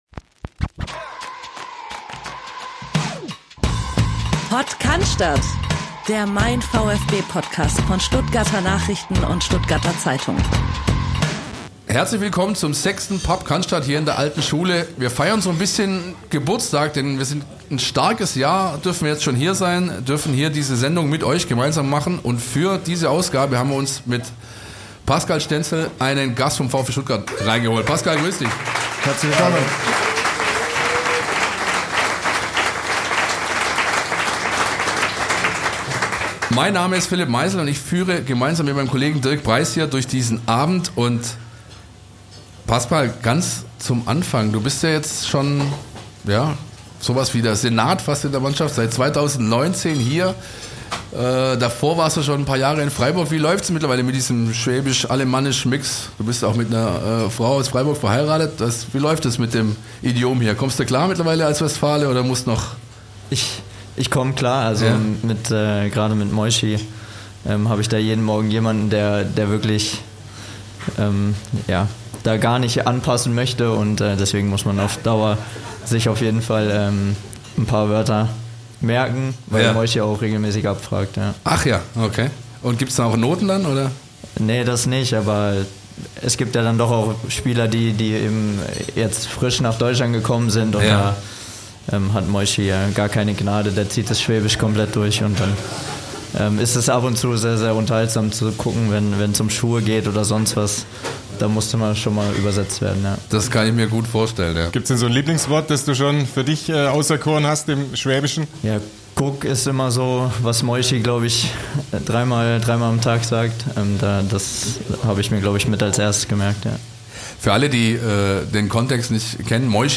Professionell, nah dran, kompakt aufbereitet - wöchentlich sprechen unsere VfB-Reporter über den VfB Stuttgart, seine aktuelle Form und die Themen, die die Fans bewegen.